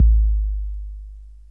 TR808BD3.WAV